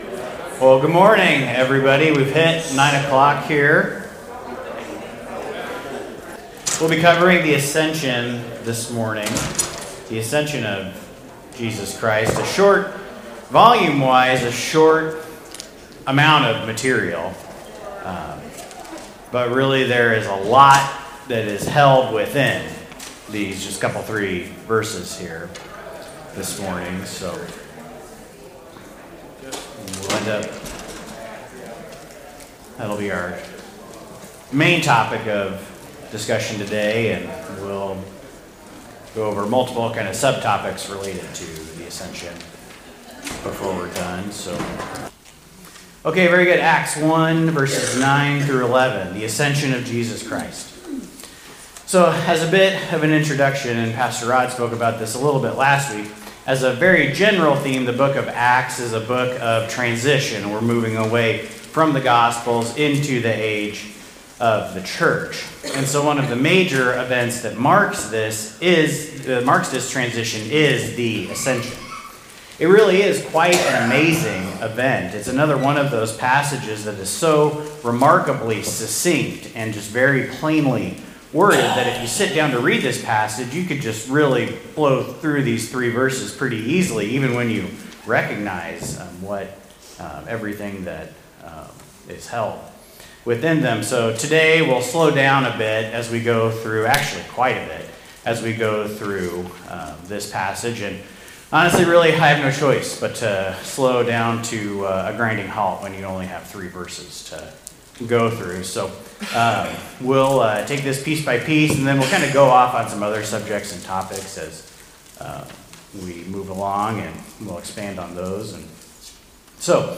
Sermons Podcast - The Ascension of Jesus Christ | Free Listening on Podbean App